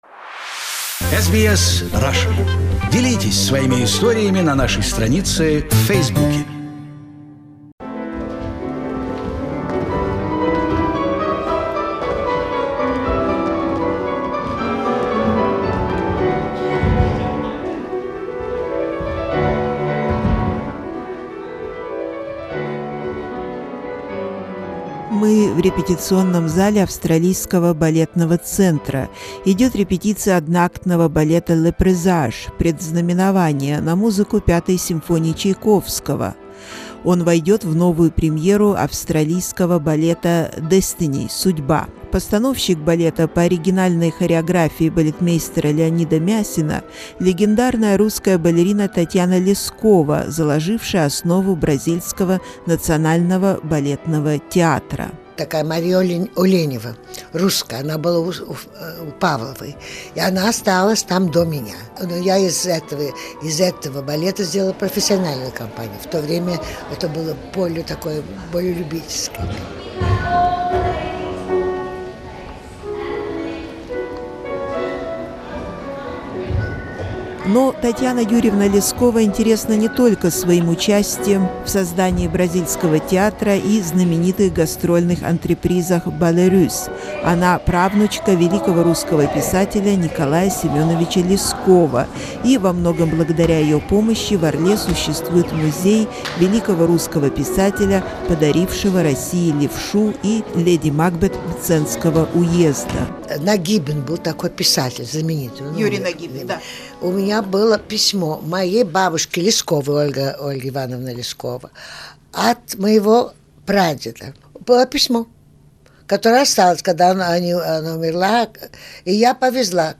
Tatiana Leskova is one of the last surviving dancers from the world famous Imperial Russian Ballet. We spoke with her in 2007 at the rehearsal when she was invited to help restore Leonide Massine first symphonic ballet, Les Presages for Australia Ballet production. The legendary ballerina spoke of her upbringing by the Russian emigre parents in Paris and studies with Lubov Egorova .